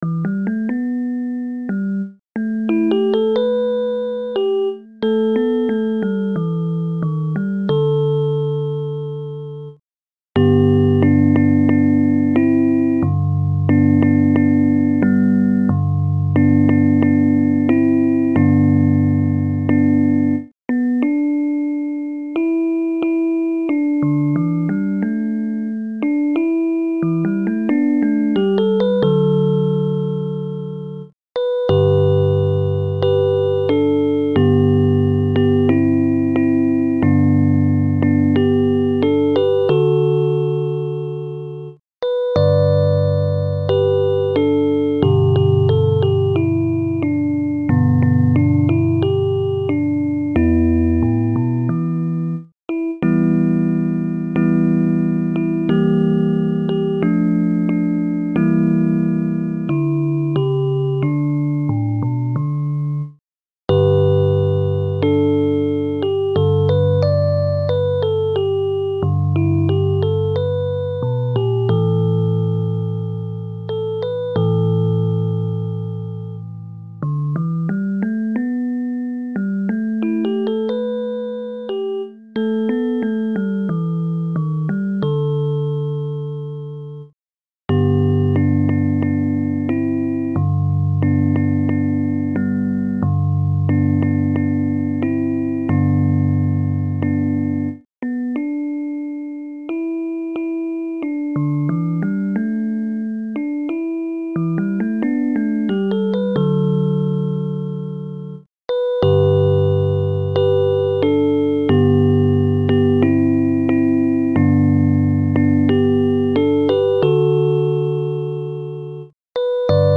童謡・唱歌：『叱られて』